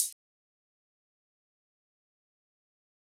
Closed Hats
Love In The Sky Hi-Hat.wav